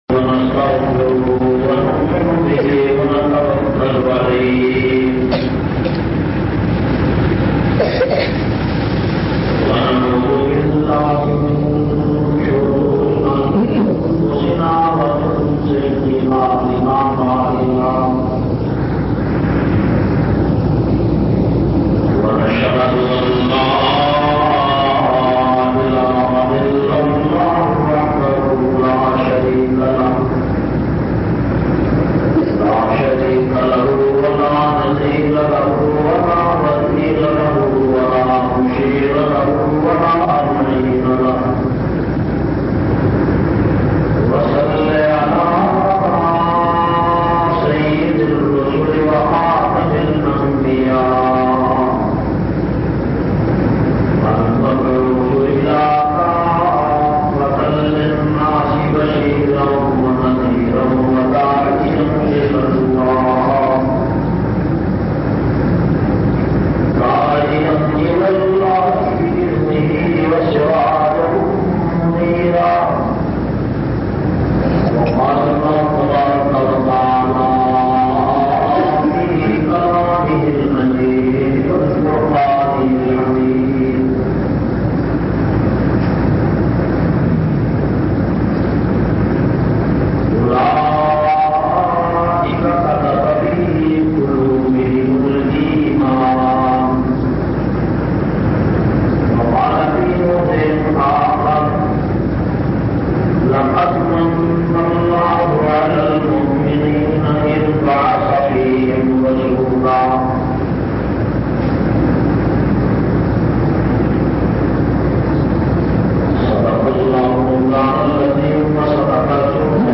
537- Seerat un Nabi Jumma khutba Jamia Masjid Muhammadia Samandri Faisalabad.mp3